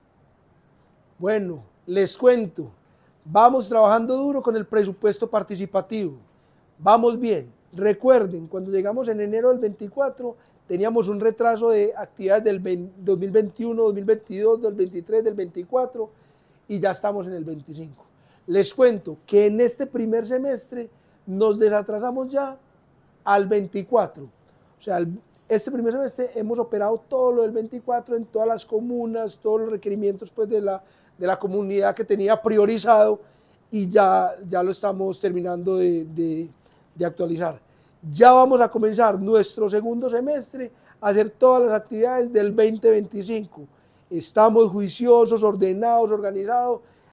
Declaraciones del director del Inder, Eduardo Silva Meluk En total, 75.436 personas participaron en las actividades programadas con el Presupuesto Participativo.
Declaraciones-del-director-del-Inder-Eduardo-Silva-Meluk.mp3